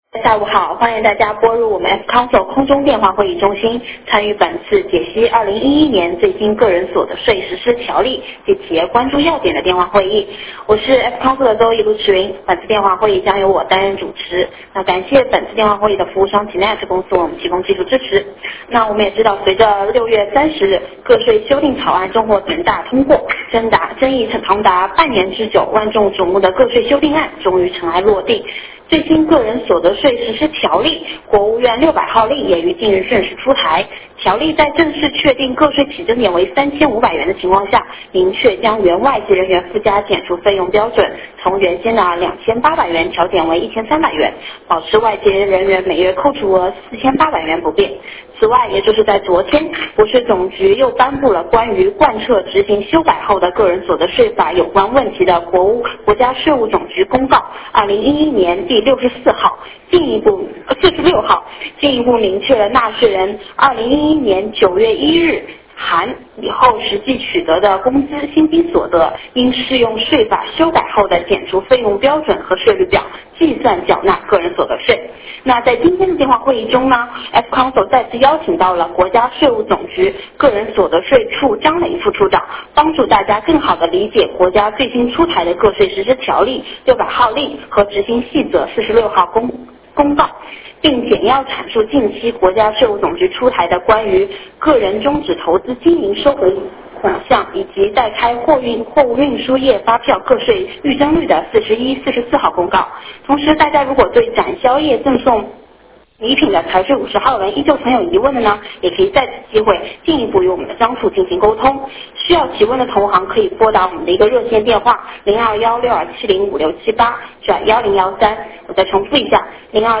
电话会议
互动问答